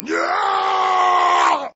scream13.ogg